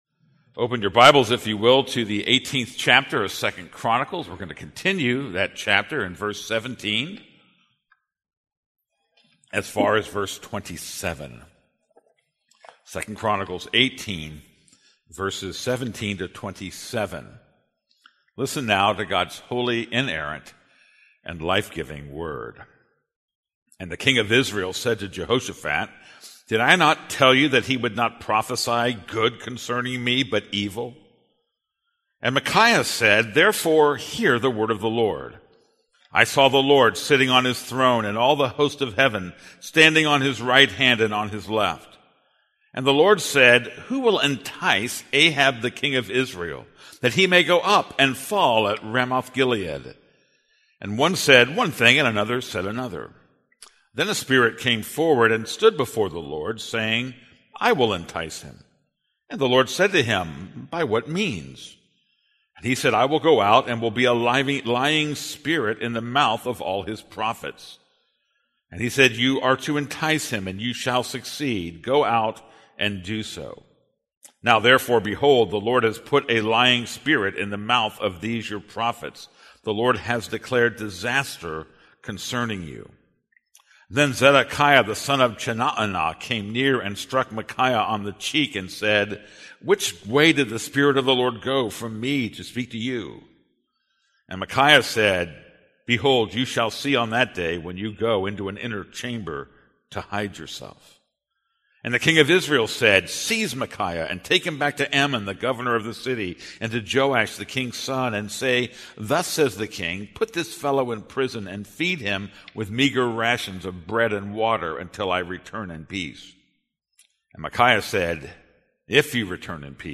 This is a sermon on 2 Chronicles 18:18-27.